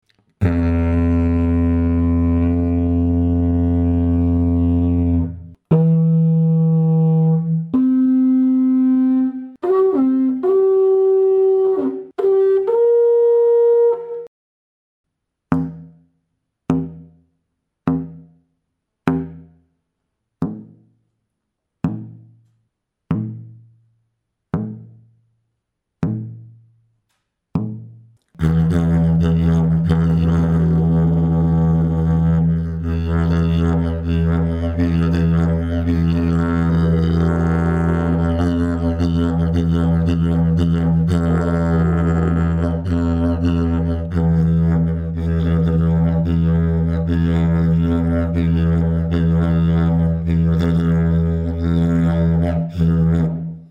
Dg537 ist ein Didgeridoo meines Modells 048 gestimmt in F2 -30, mit dem Overblow unter der Oktave, auf E3.
Der sehr stabile und leicht zu spielende Grundton hat guten Resonanzwiderstand. Der erste Overblow liegt mit der schmalen Konusform etwa einen Halbton unter der Oktave. Die Form erzeugt einen nicht so stark dröhnenden Grundton, der sich gut mit Stimmeffekten modulieren lässt und dadurch auch Mikrophone nicht so leicht übersteuert.
Fundamental note, draw ranges and overblows at 24� C, concert pitch A=440Hz: F2-35 (D#2 to F2-20) // E3-30 (-50, +0) / C4-10 (-20, +0) / G4-20 / Bb+20 Dg537 Technical sound sample 01